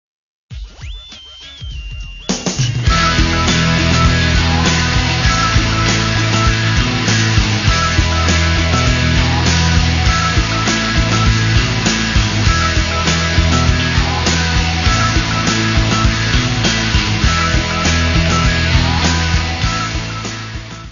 Guitarra
Baixo
Bateria
Coro
Área:  Pop / Rock